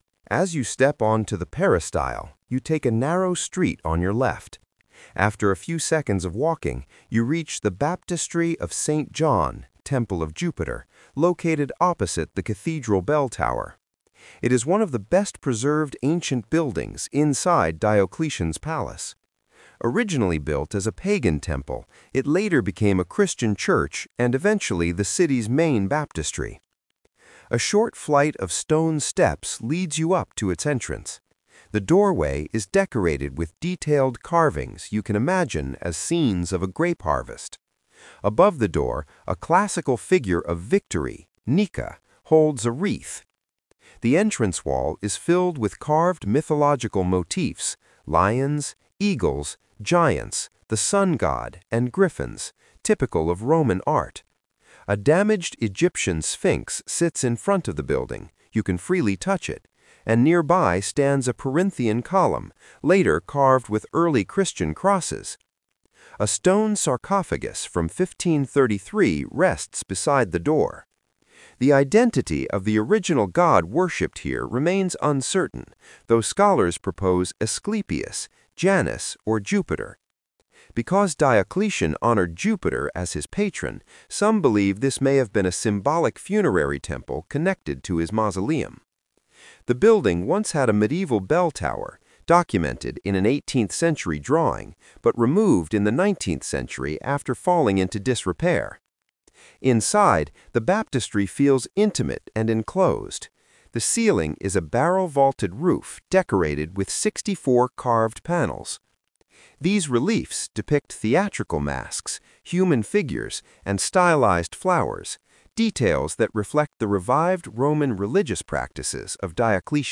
Accessible Audio Guide of the Split Cathedral Complex – in Croatian and English
The guide provides adapted audio descriptions of key sites within the complex, including Diocletian’s Palace, the Split Cathedral, the Treasury of the Split Cathedral, and the Baptistery, enabling users to enjoy a more inclusive and accessible sightseeing experience.